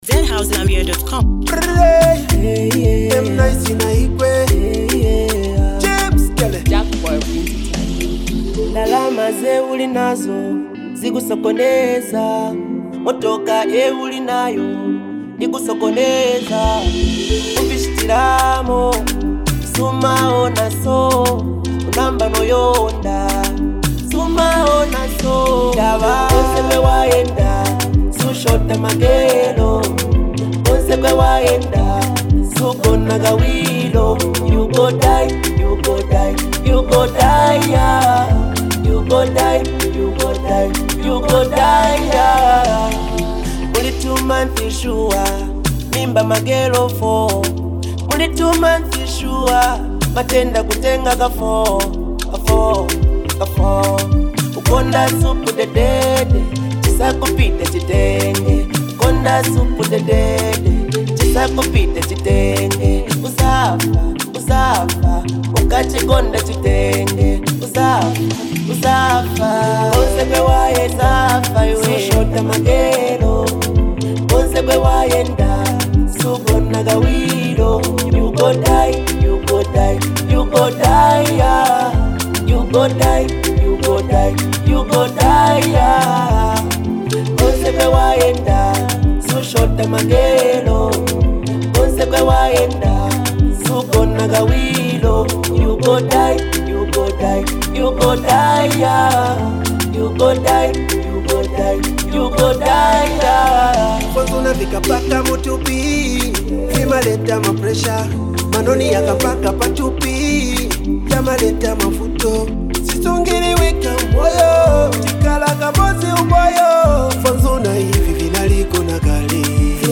a smooth, relatable vibe